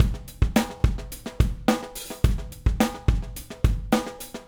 Retro Funkish Beat 01.wav